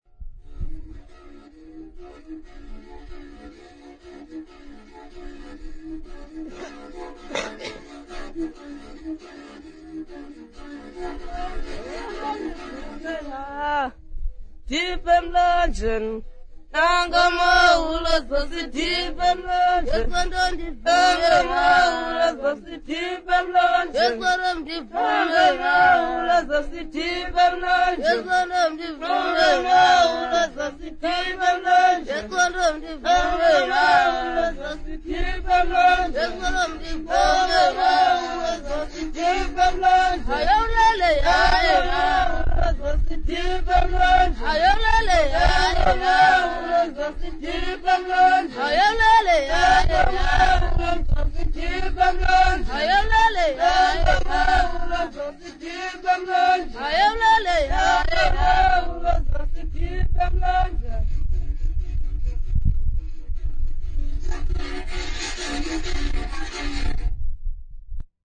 Xhosa boys
Folk songs, Xhosa South Africa
Stringed instrument music South Africa
Africa South Africa Lumko, Eastern Cape sa
field recordings
Traditional Xhosa song with inkinge accompaniment.